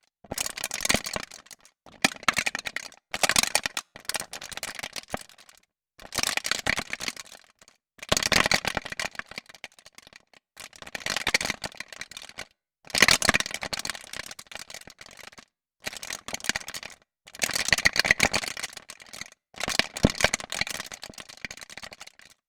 Fortunately for me, I have my Hydrasynth to keep me warm with all its firewood crackles :
Realistic sounds. But I hear something else than firewood crackles.
Fm, noise… ?
Yeah basically FM mutators, chorus, flanger, and there you go.
Sounds more like popcorn popping.